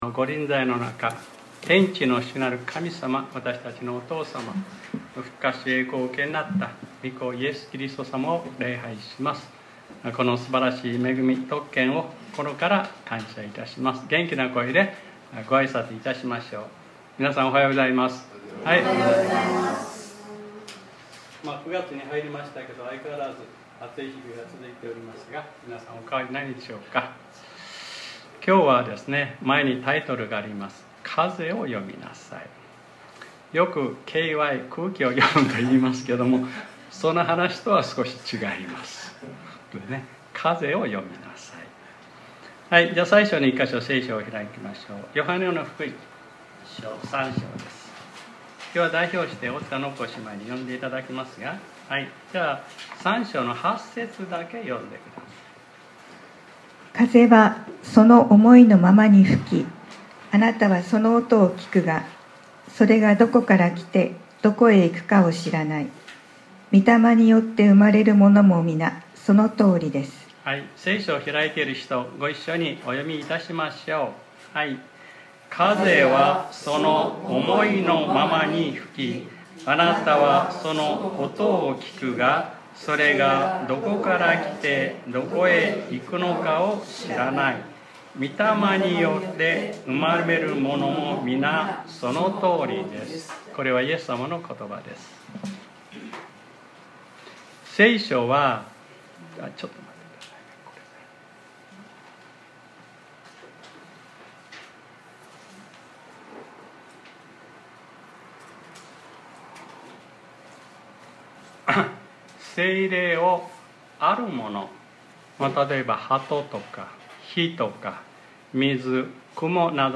2025年09月07日（日）礼拝説教『 風を読みなさい 』 | クライストチャーチ久留米教会